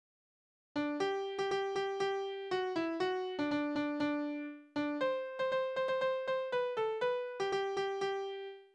Tonart: G-Dur
Taktart: 4/8
Tonumfang: kleine Septime
Besetzung: vokal
Anmerkung: Vortragsbezeichnung: Polka